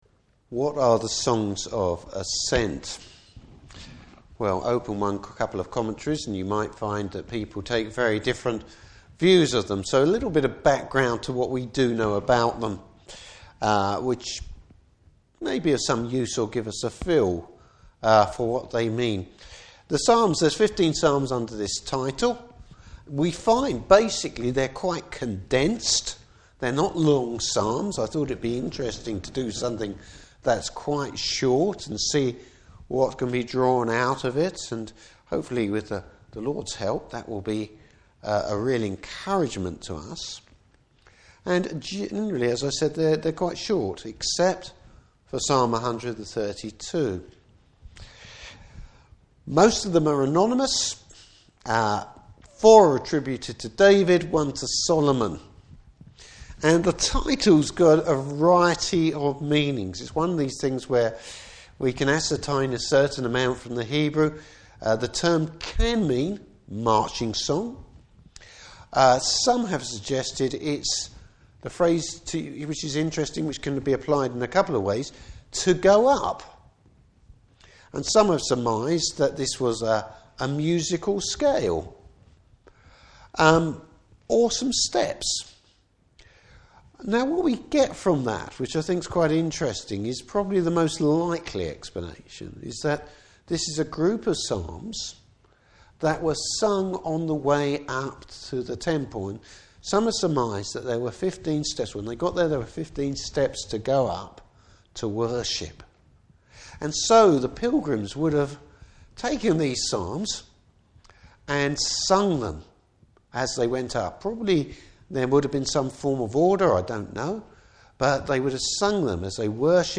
Service Type: Evening Service Bible Text: Psalm 120.